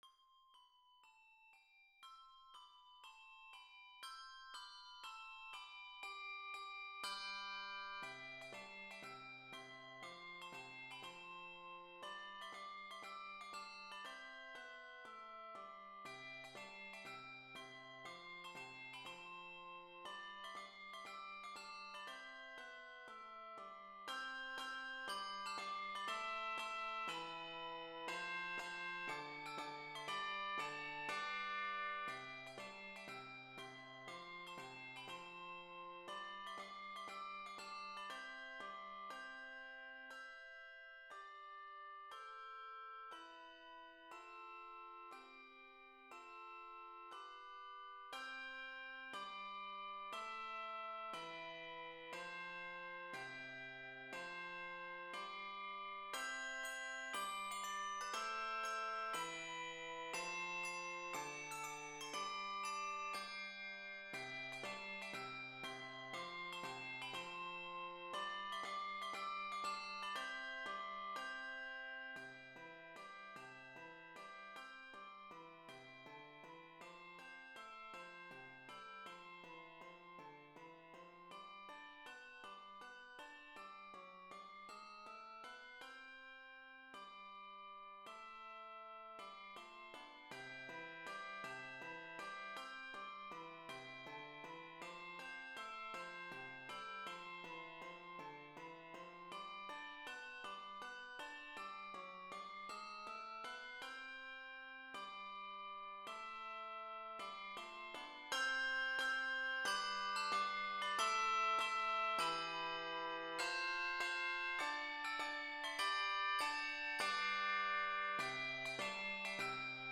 This is my first attempt at writing for hand bells. This is my arrangement of an old hymn.
HYMN MUSIC